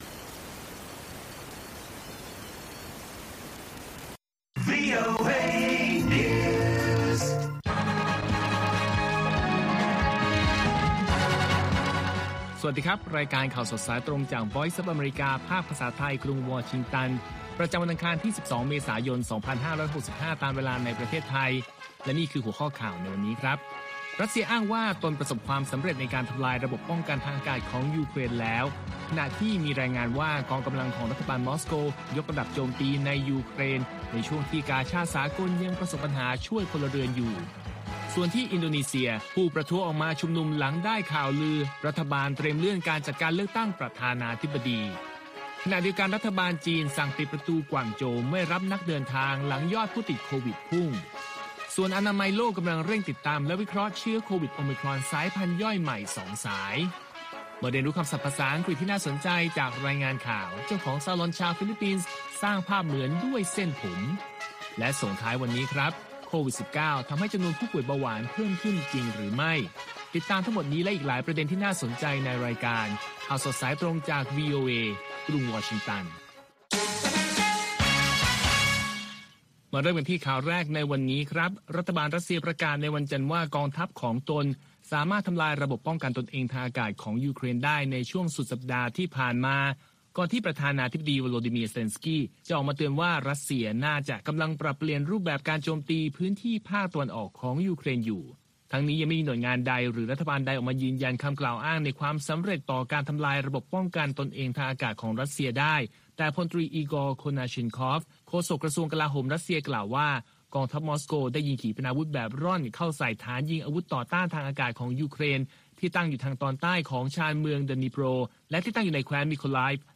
ข่าวสดสายตรงจากวีโอเอ ภาคภาษาไทย ประจำวันอังคารที่ 12 เมษายน 2565 ตามเวลาประเทศไทย